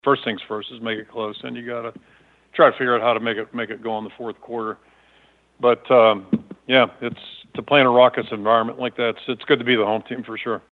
Coming off a Rose Bowl win Ferentz says the Nittany Lions are one of the nation’s top teams.